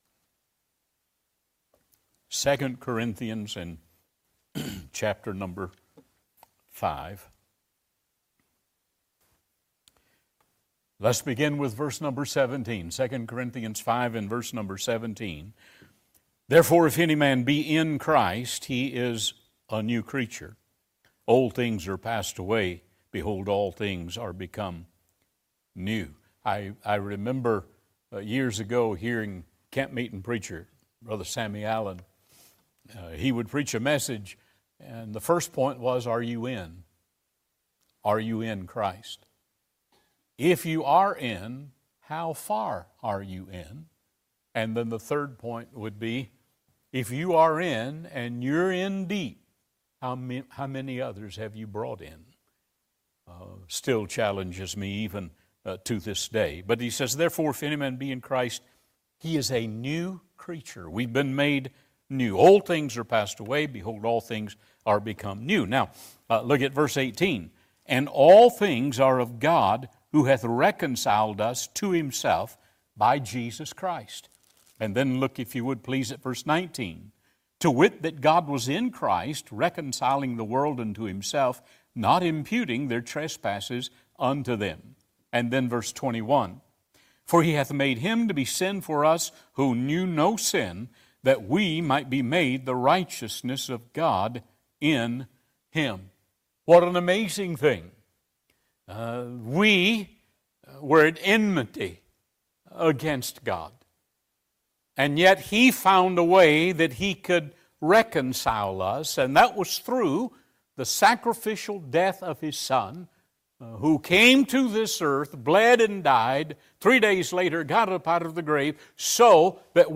2025 Missions Conference Passage: 2 Cor. 5:10-21 Service Type: Sunday PM Topics